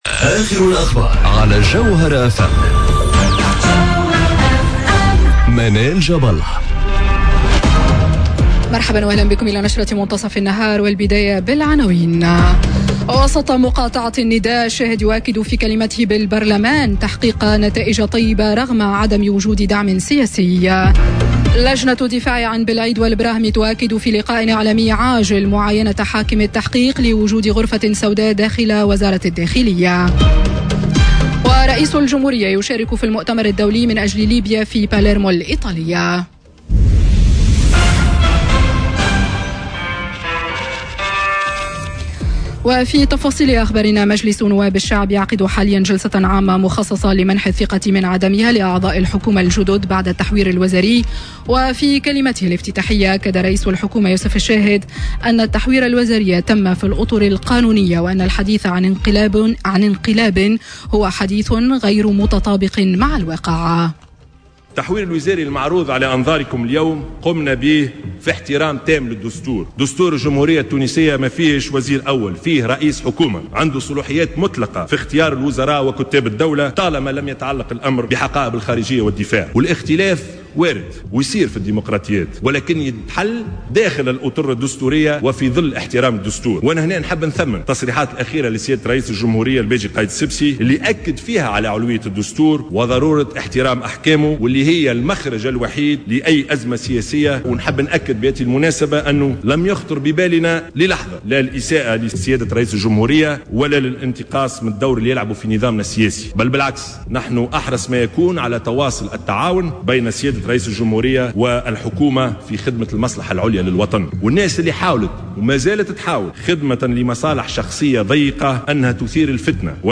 نشرة أخبار منتصف النهار ليوم الإثنين 12 نوفمبر 2018